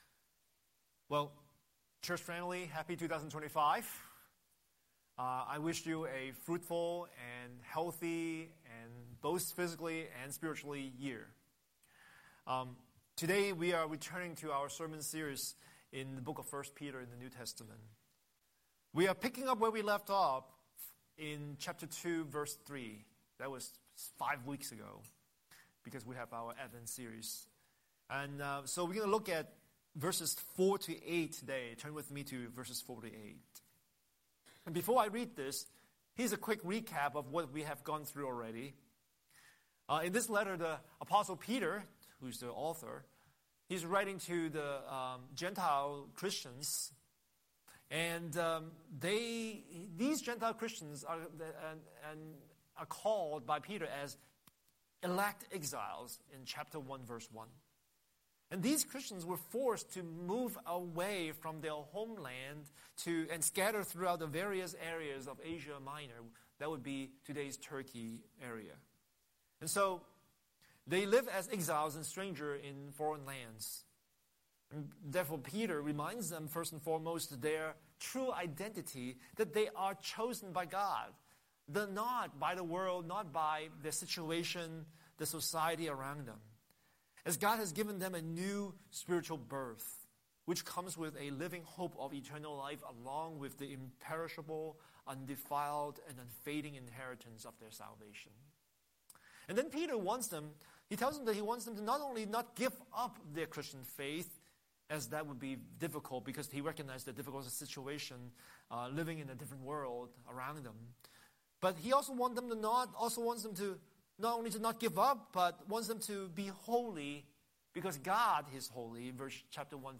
Scripture: 1 Peter 2:4-8 Series: Sunday Sermon